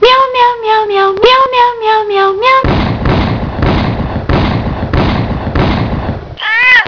Meow
meow.wav